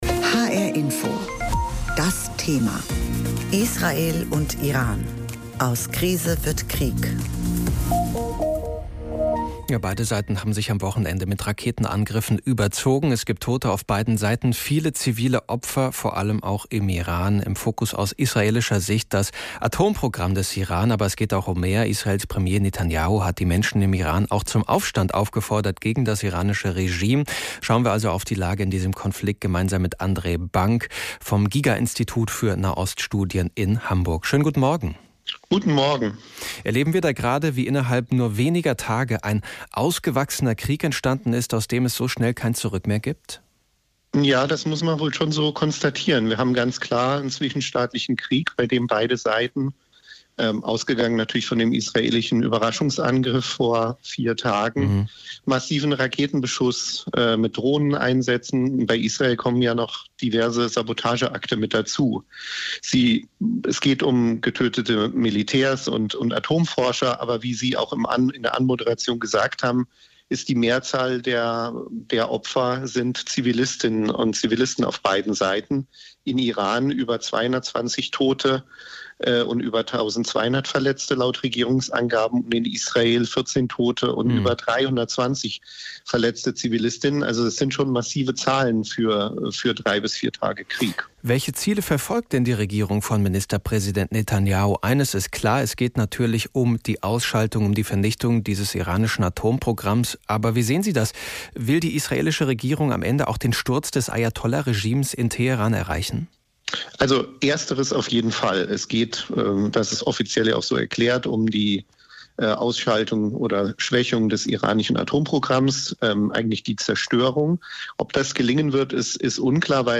hrINFO-Interview.mp3